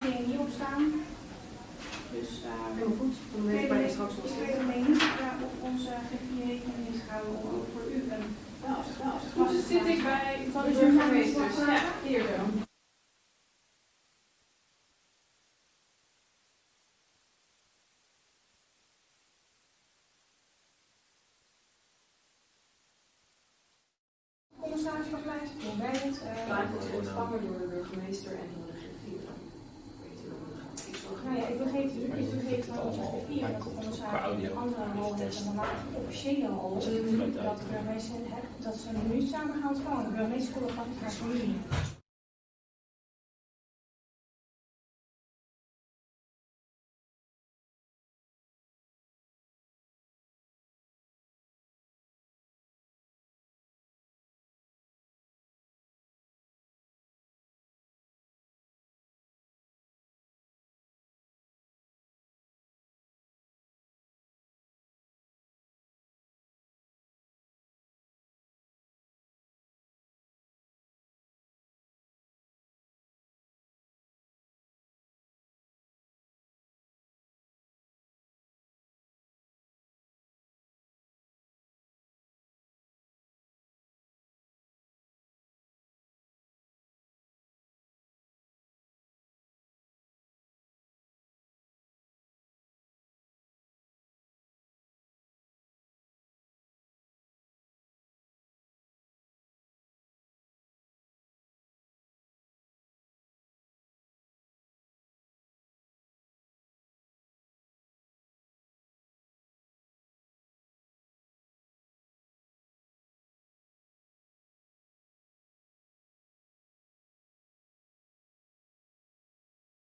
Afscheidsraad & receptie burgemeester
Locatie: Kasteel van Rhoon
Toespraak van wethouder Polder, namens het college
Toespraak van de heer Kolff, commissaris van de Koning